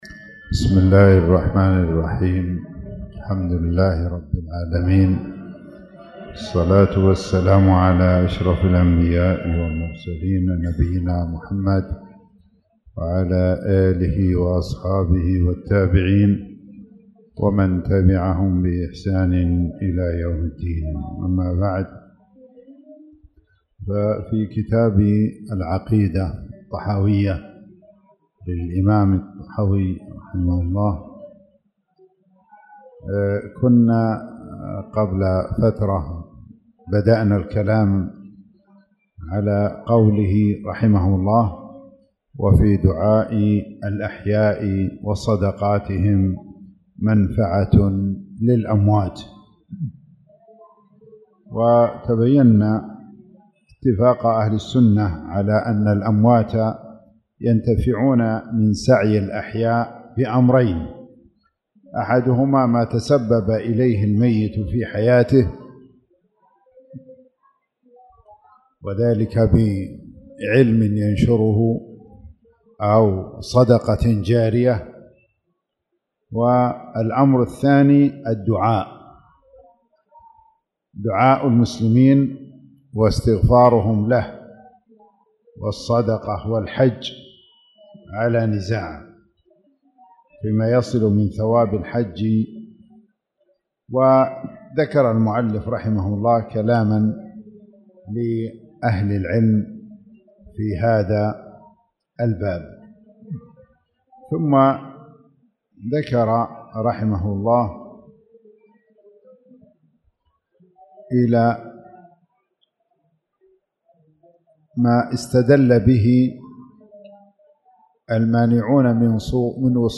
تاريخ النشر ٦ شوال ١٤٣٧ هـ المكان: المسجد الحرام الشيخ